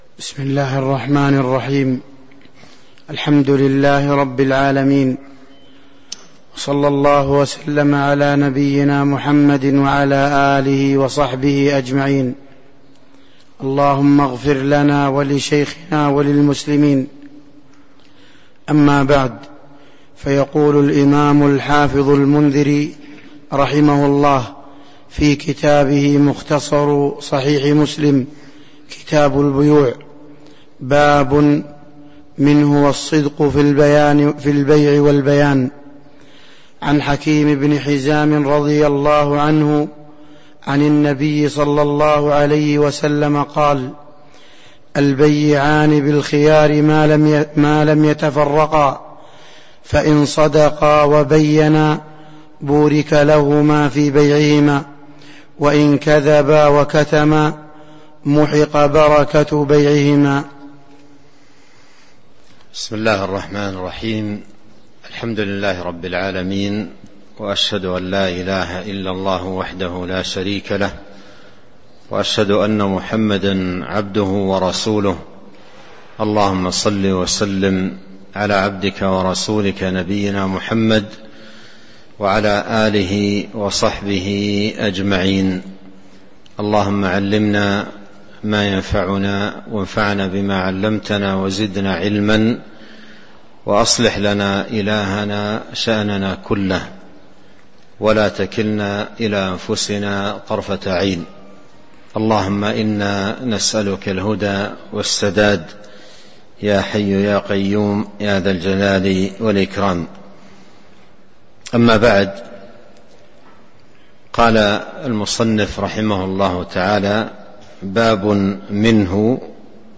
تاريخ النشر ٧ صفر ١٤٤٣ هـ المكان: المسجد النبوي الشيخ